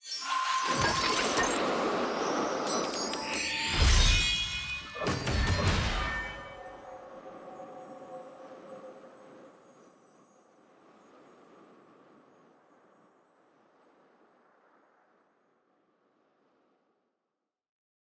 sfx-clash-capsule-tier-3-ante-5.ogg